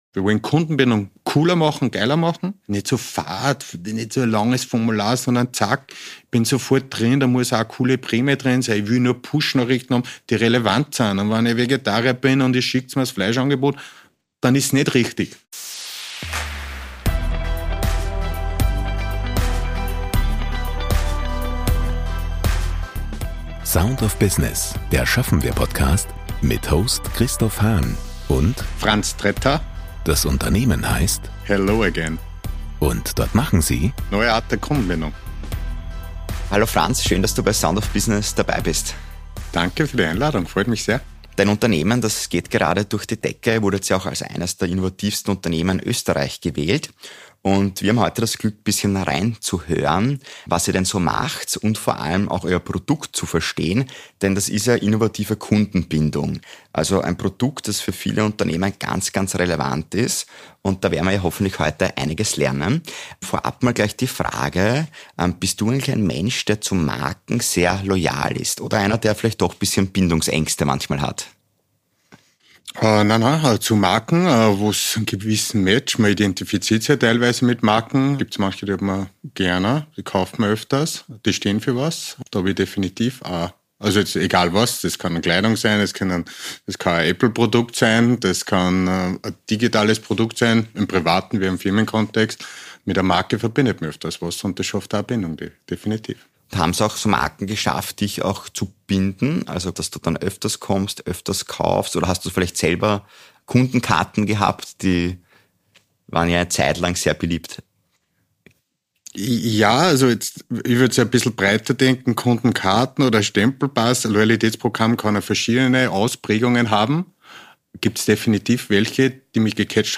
Heute im Gespräch